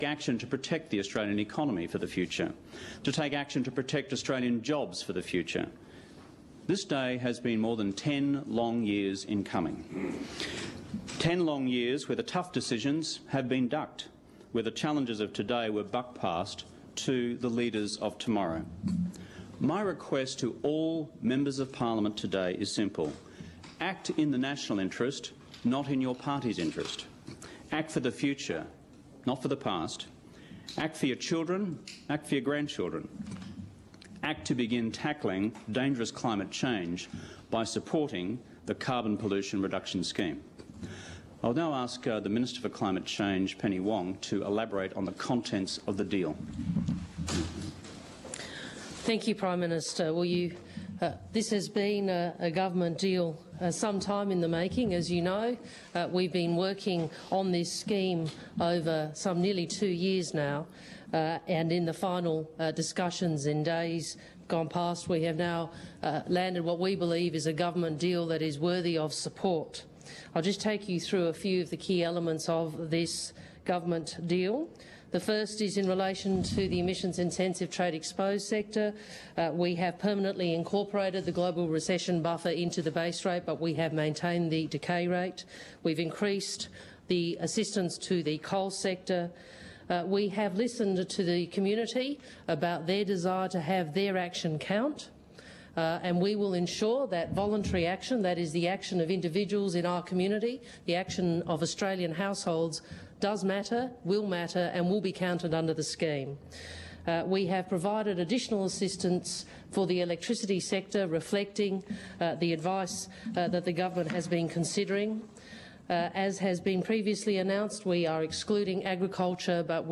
Breaking: Kevin Rudd and Penny Wong – press conference